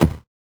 CS_VocoBitA_Hit-10.wav